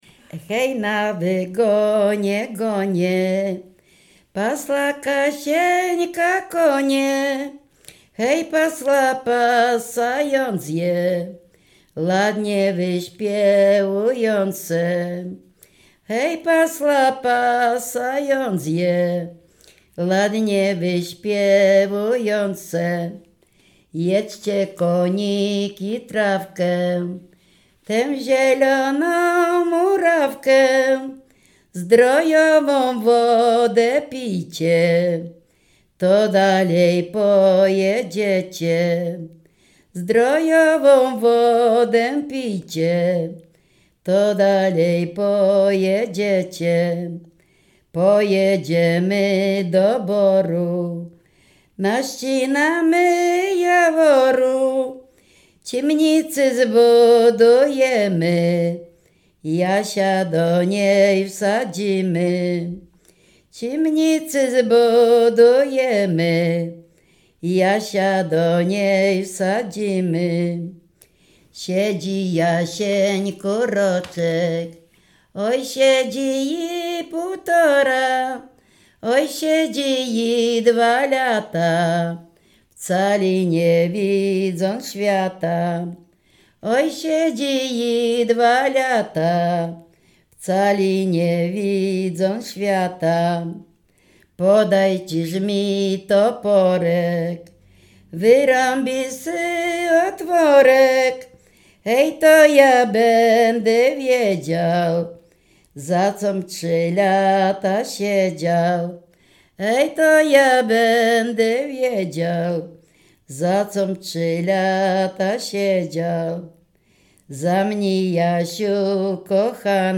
Dolny Śląsk
W wymowie Ł wymawiane jako przedniojęzykowo-zębowe;
e (é) w końcu wyrazu zachowało jego dawną realizację jako i(y)
Obyczajowa